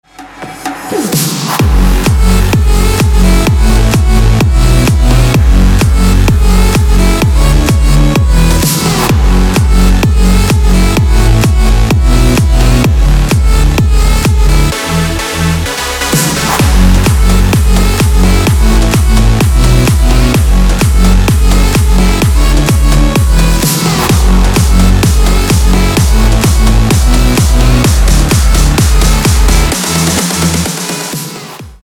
• Качество: 256, Stereo
клубняк
electro
Электро